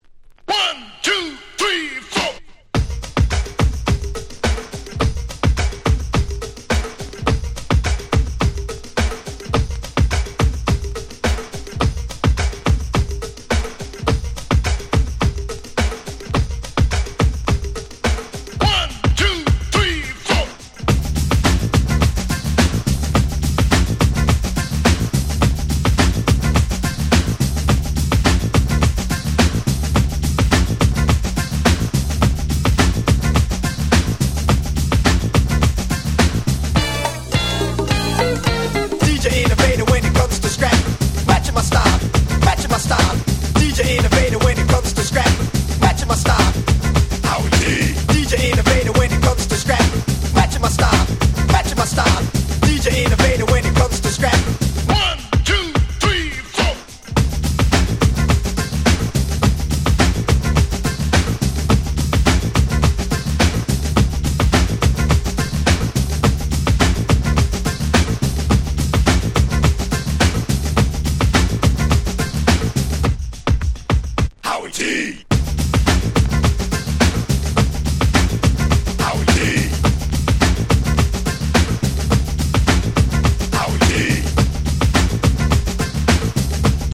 91' Super Hit Jazzy Break !!
R&B